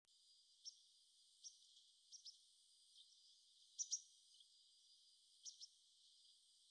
71-2嘉義大白鶺鴒2.WAV
白鶺鴒(白面黑背亞種) Motacilla alba leucopsis
錄音環境 溝渠邊草地
行為描述 覓食後飛
收音: 廠牌 Sennheiser 型號 ME 67